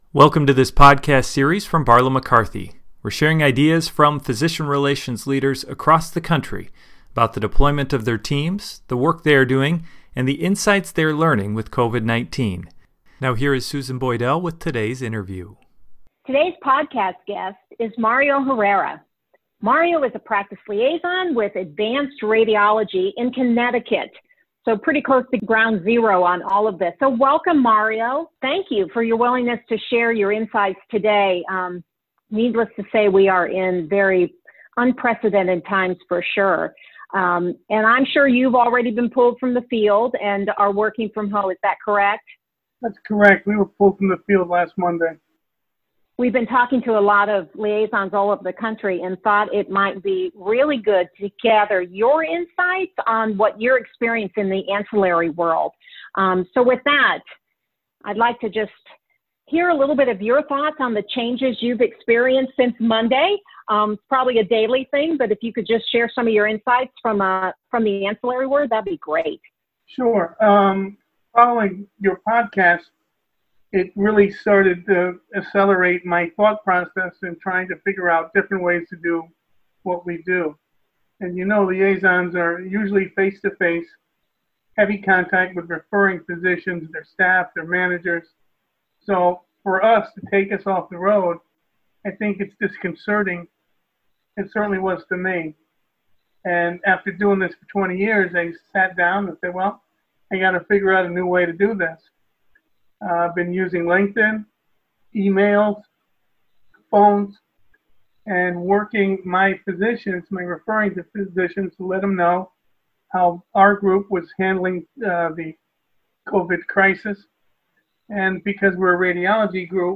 B/Mc Podcast: COVID-19 and Physician Relations – Leadership Interview #2